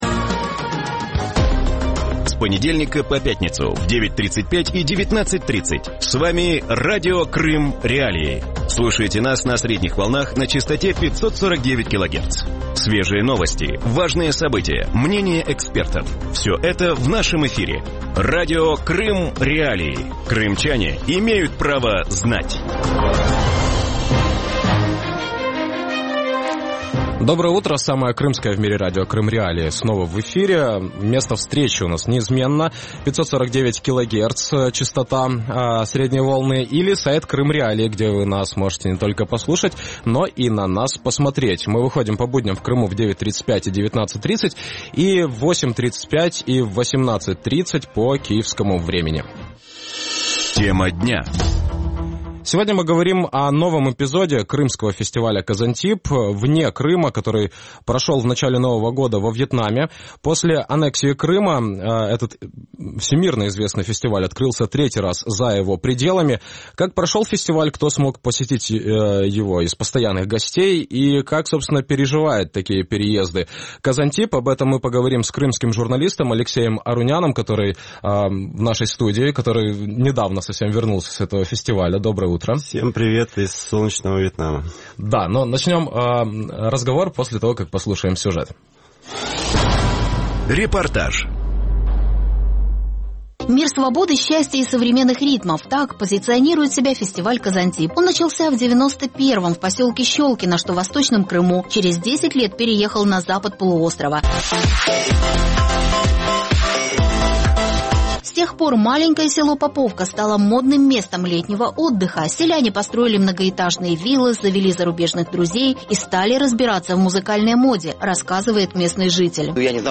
В утреннем эфире Радио Крым.Реалии говорят о новом эпизоде крымского фестиваля «КаZантип», который прошел во Вьетнаме.